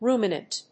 音節ru・mi・nant 発音記号・読み方
/rúːmənənt(米国英語), ˈru:mʌnʌnt(英国英語)/